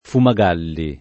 [ fuma g# lli ]